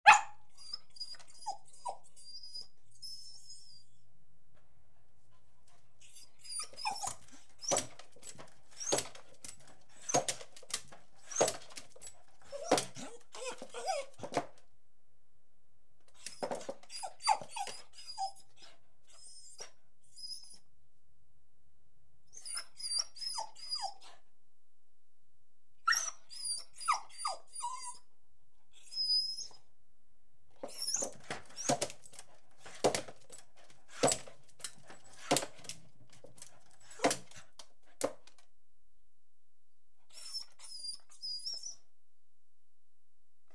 Dog_Crying-01.wav